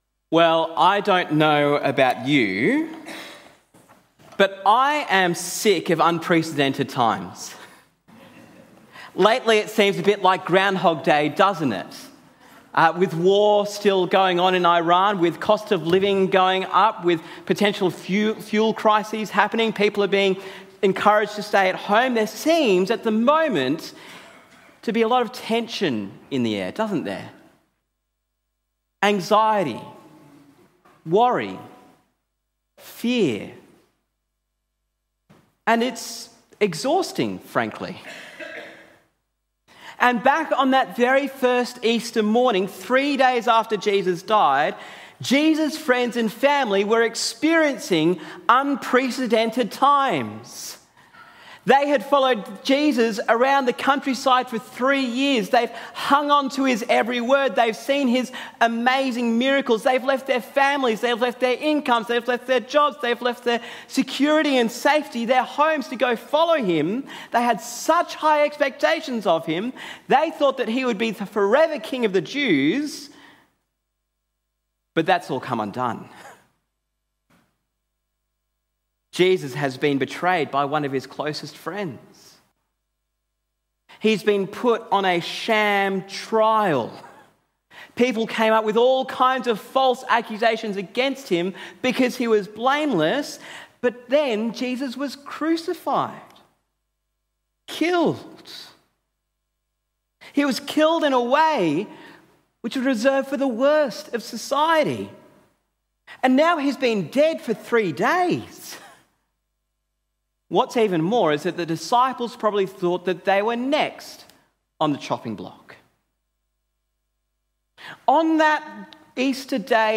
Sermon on Mark 16 - Easter Day
Easter Day This is our sermon from our Easter Day service for 2026 Download Sermon Transcript and Questions Download Series resource Download Sermon Audio See Other Sermons in Series